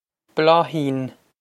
Bláithín Blah-heen
Blah-heen
This is an approximate phonetic pronunciation of the phrase.